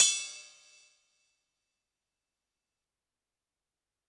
Drums_K4(18).wav